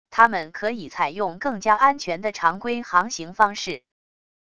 他们可以采用更加安全的常规航行方式wav音频生成系统WAV Audio Player